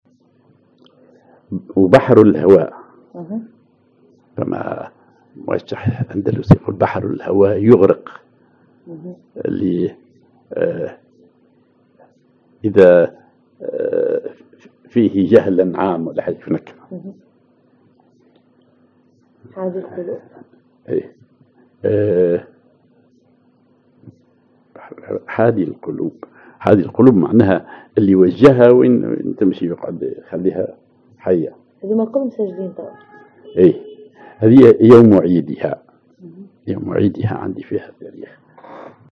Maqam ar رصد الذيل
genre أغنية